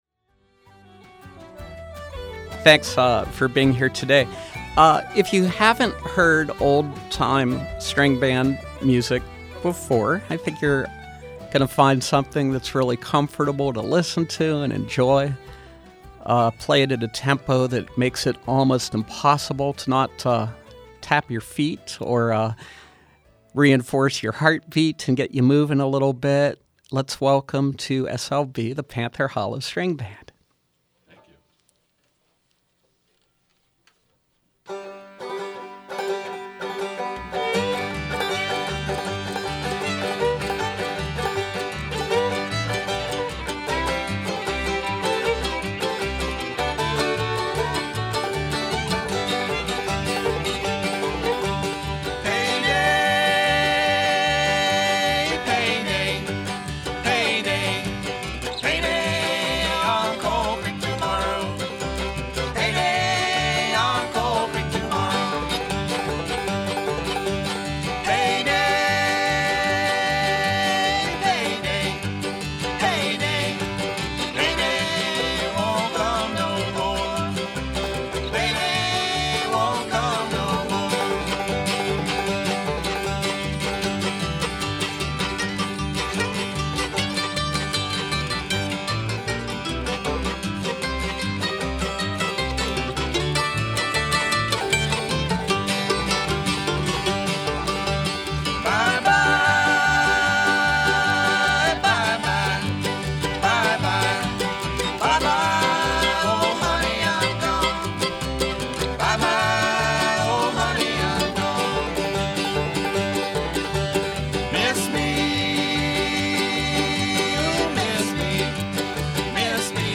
Bluegrass and old-time music